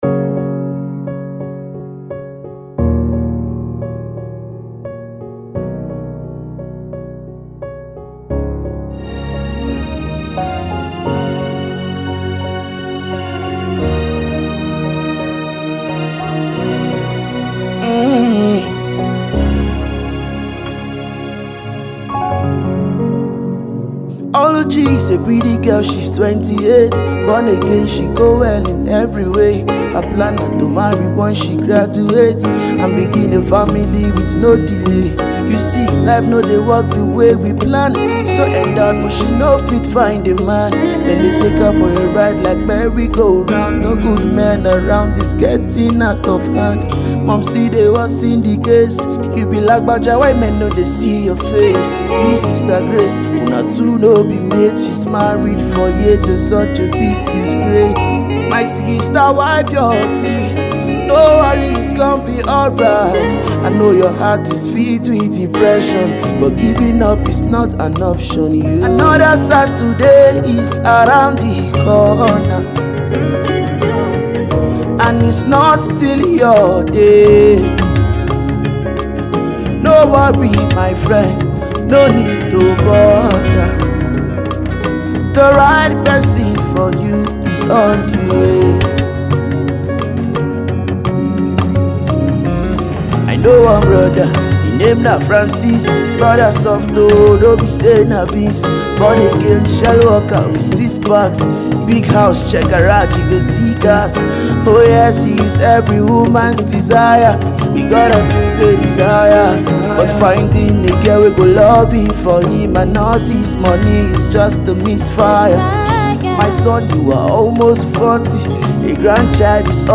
Gospel/inspirational rapper and singer